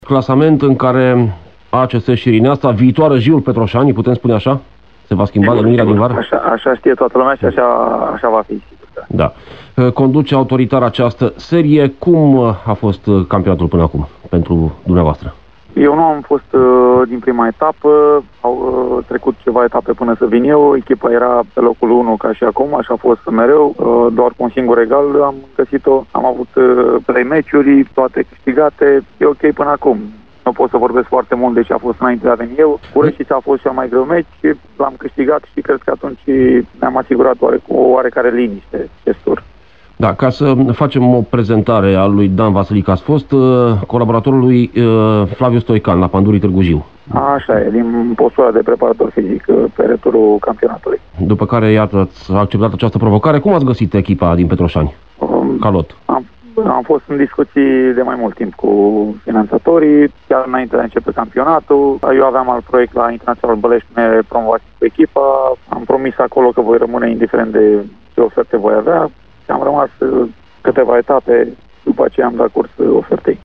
a vorbit despre echipa sa într-un interviu pentru Radio Timișoara